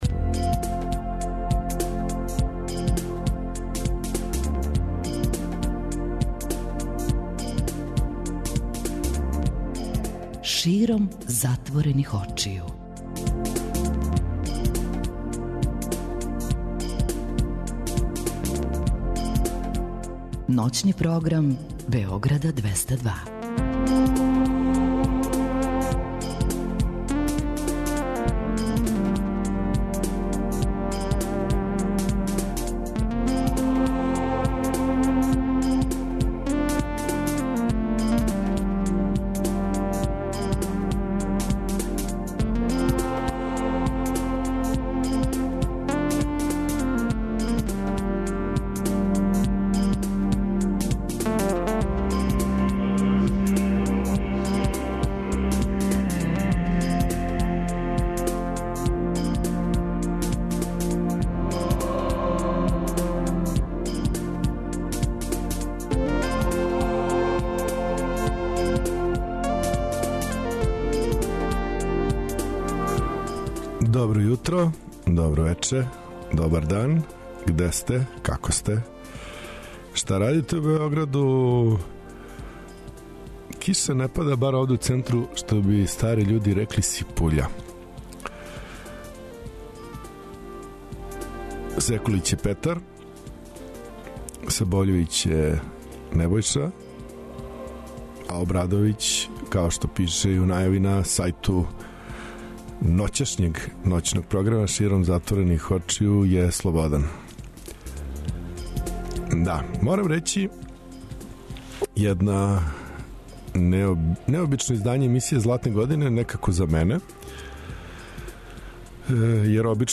Широм затворених очију, ново издање емисије Златне године, музика 60-тих и 70-тих, када је музика хтела да промени свет, а свет је променио музику.